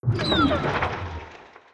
Windmill_Stop.wav